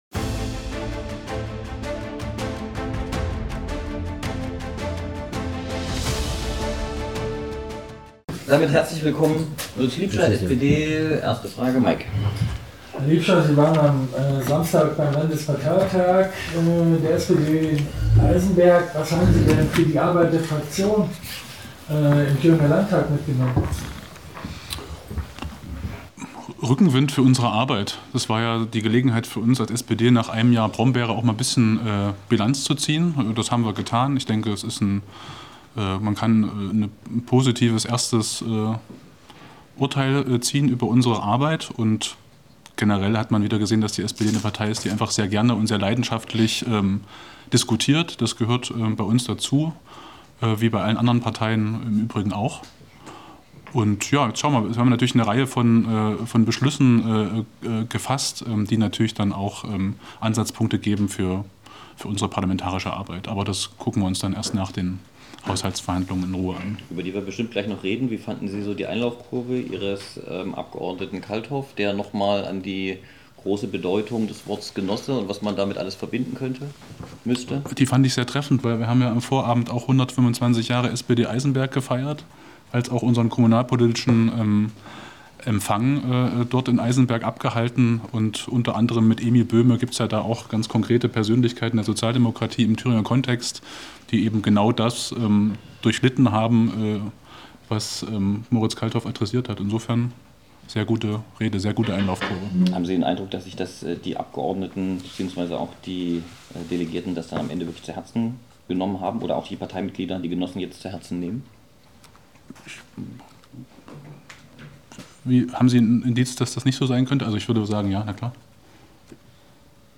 Das Konzert �Weinklang� in voller L�nge
Gambist